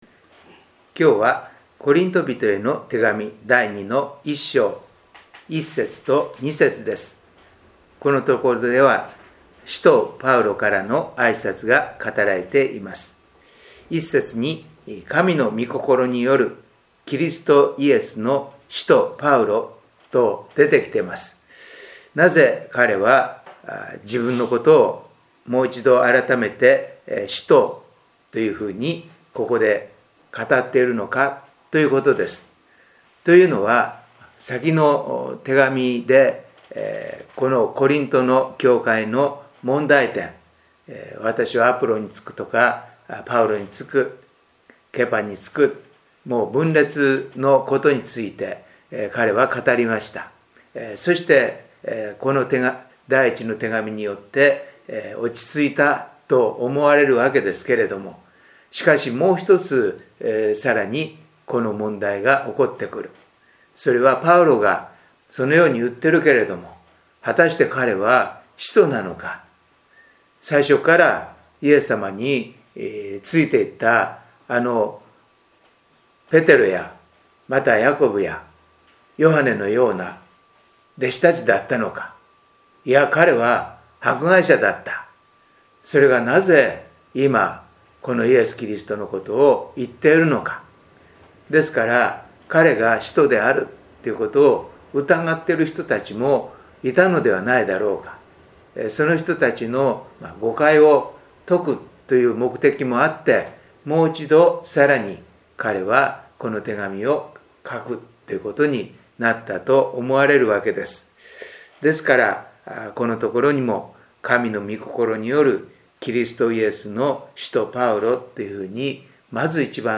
礼拝メッセージ「全ての人に伝える福音」│日本イエス・キリスト教団 柏 原 教 会